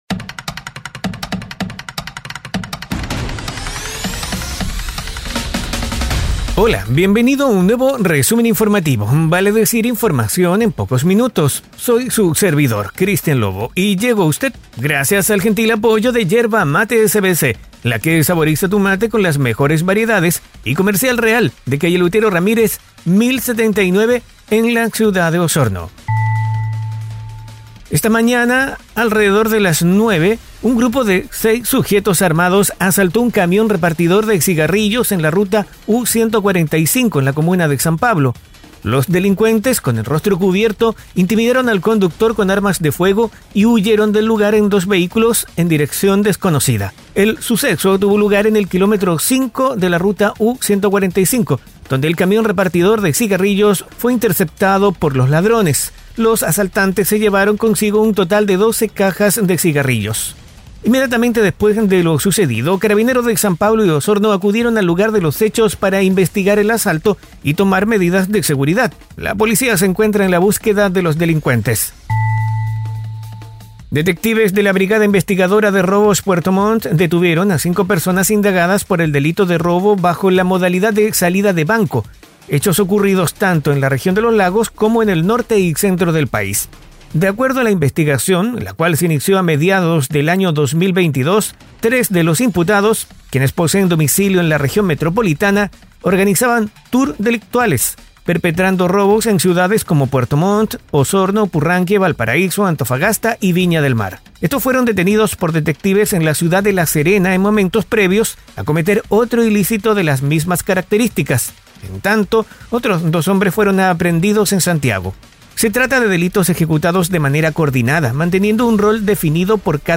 🐺Resumen informativo es un audio podcast con una decena de informaciones en pocos minutos, enfocadas en la Región de Los Lagos, conducido por